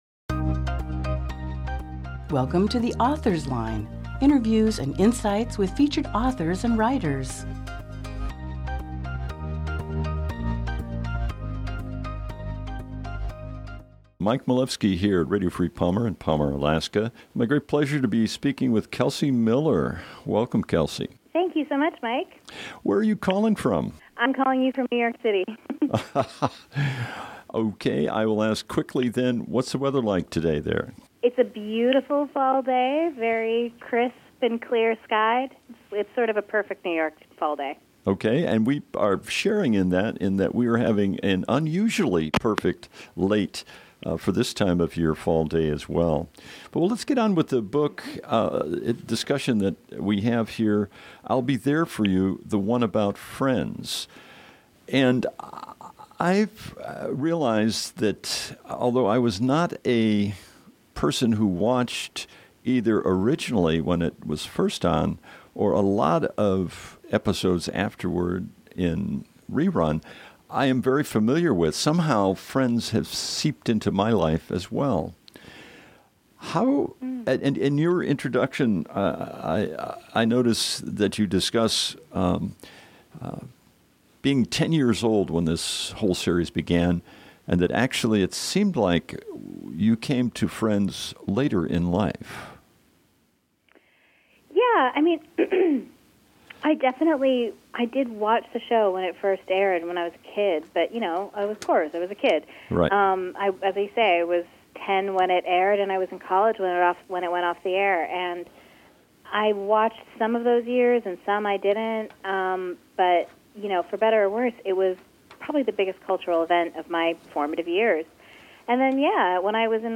Oct 31, 2018 | Author Interviews